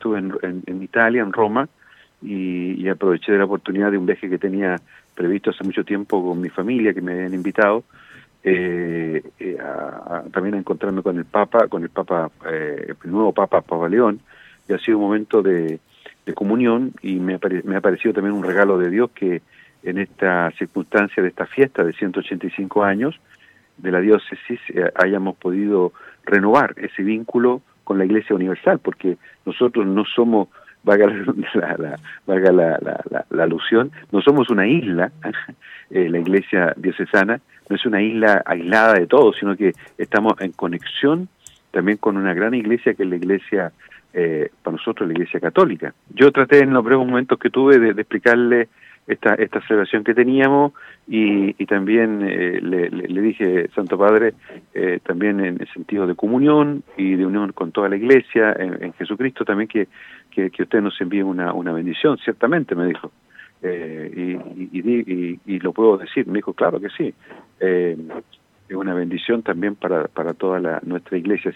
En el marco de esta conmemoración, conversamos en Radio Estrella del Mar, con Monseñor Juan María Agurto, quien comenzó haciendo un repaso por los capítulos iniciales en la historia de esta Diócesis.